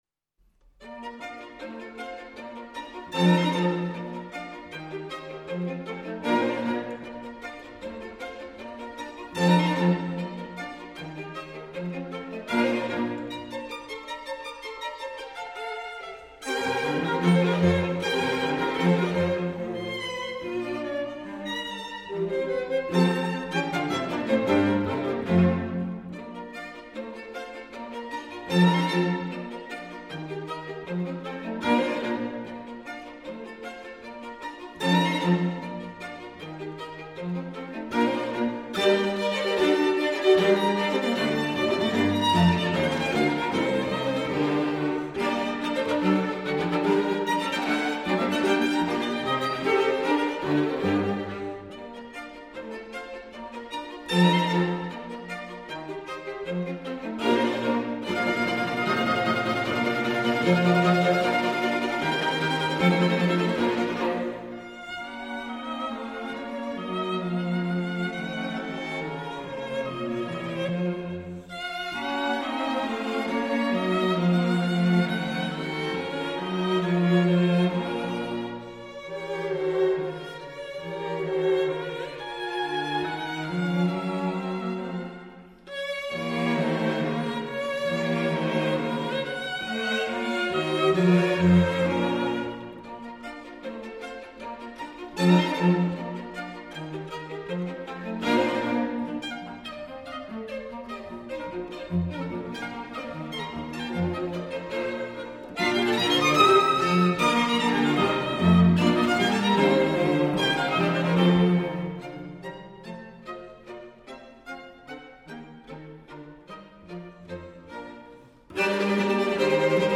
String Quartet in B flat major
Rondo Allegro grazioso